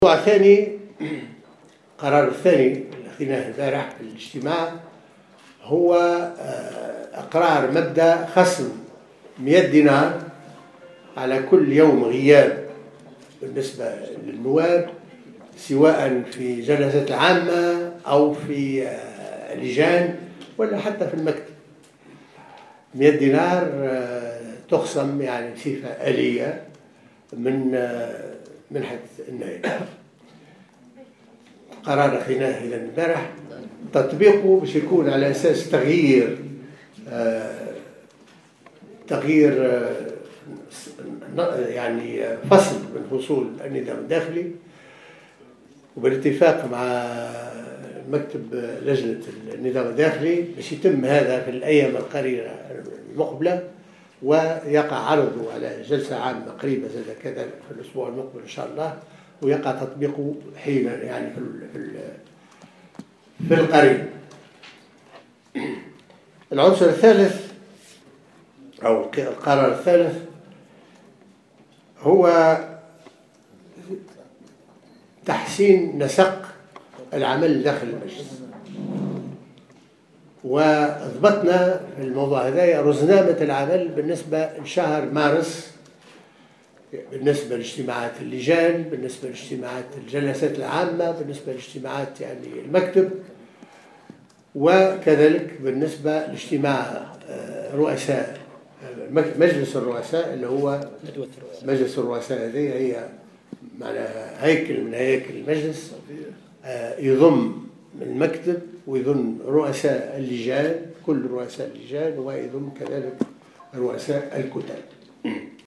أعلن محمد الناصر رئيس مجلس نواب الشعب اليوم الجمعة 26 فيفري 2016 خلال ندوة صحفية عقدها اليوم الجمعة 26 فيفري 2016 عن جملة من القرارات أهمها خصم 100 دينار على كل يوم غياب بالنسبة للنواب سواء في الجلسات العامة أو في اجتماعات اللجان ستخصم بصفة الية من أجر النائب وفق قوله.